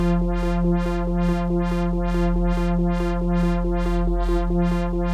Index of /musicradar/dystopian-drone-samples/Tempo Loops/140bpm
DD_TempoDroneD_140-F.wav